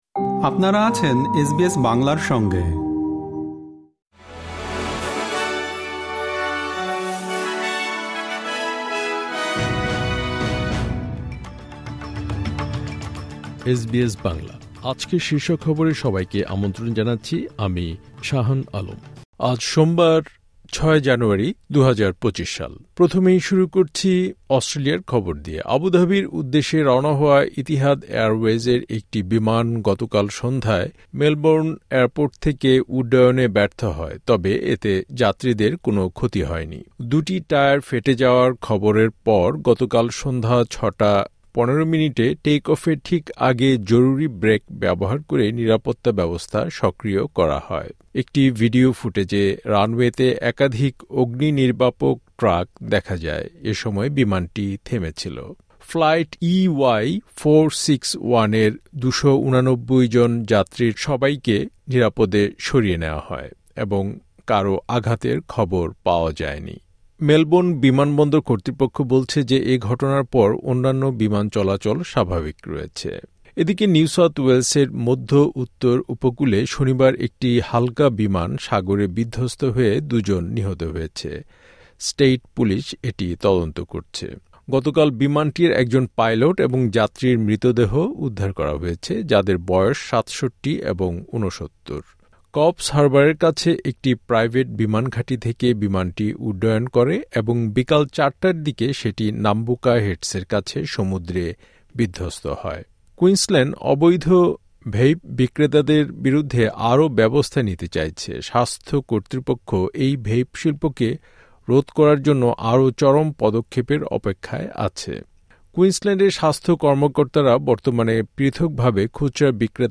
এসবিএস বাংলা শীর্ষ খবর: ৬ জানুয়ারি, ২০২৫